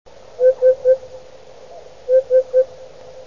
Dudek - Upupa epops